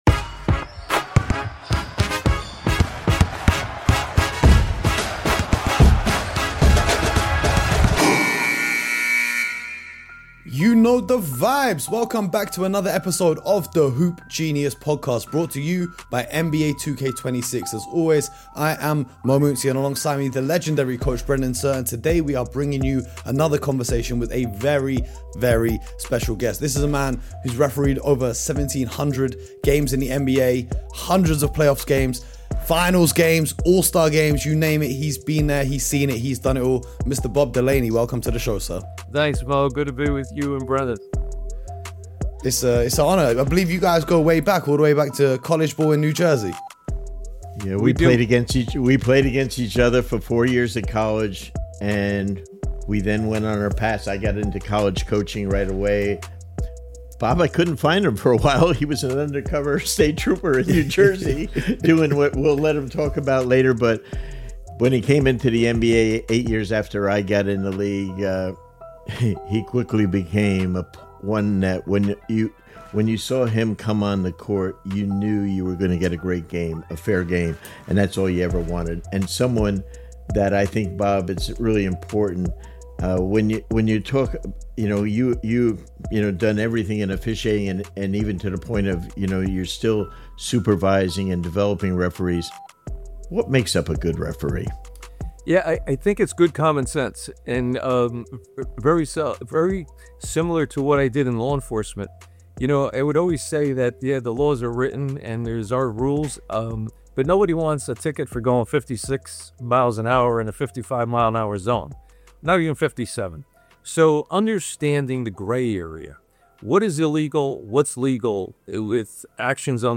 One of the most unique conversations ever on Hoop Genius.